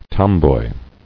[tom·boy]